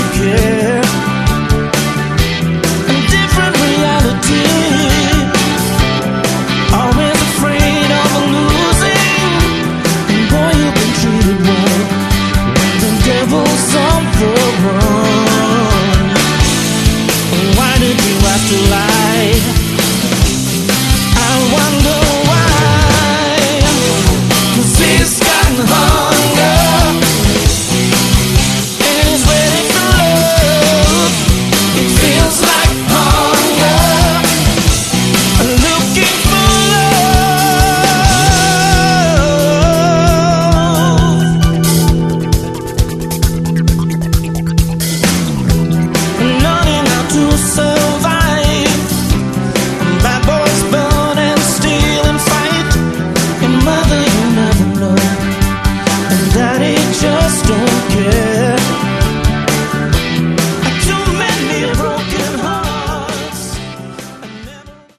Category: AOR
Guitars
Bass, Keyboards
Drums